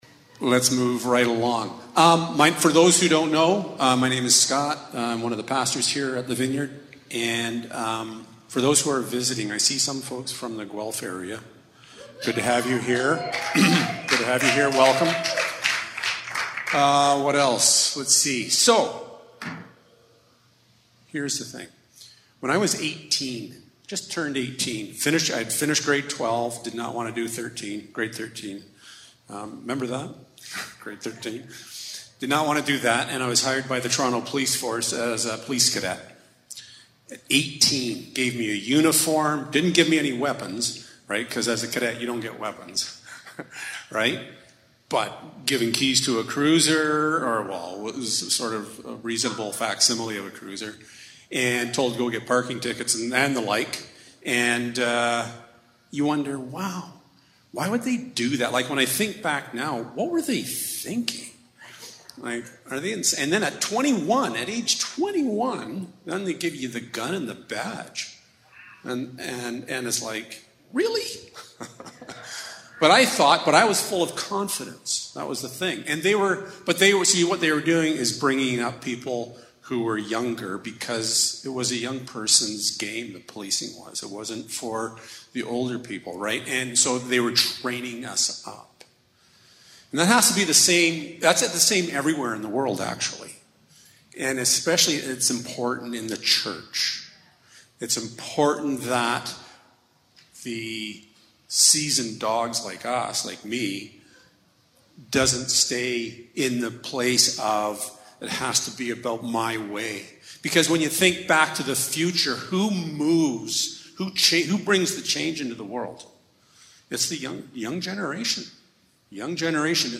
Jesus-Champion of Faith Service Type: Sunday Morning Guest Speaker